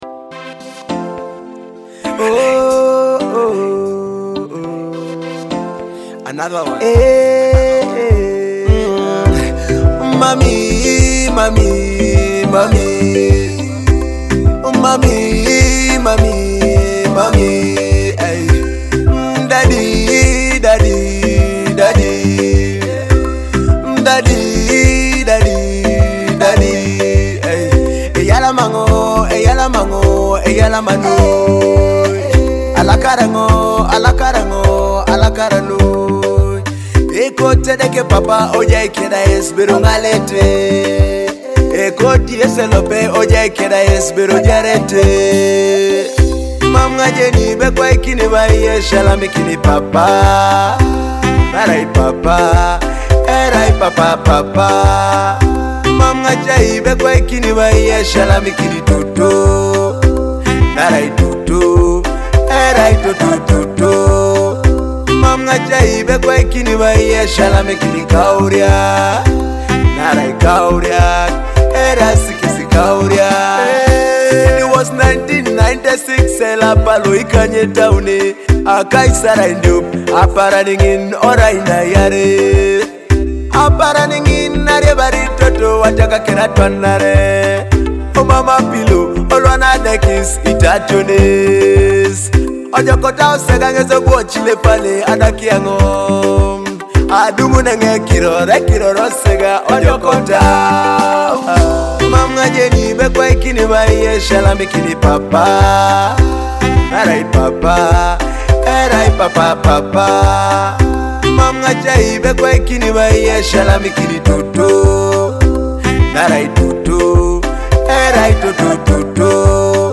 ambient, electronic, and heartfelt music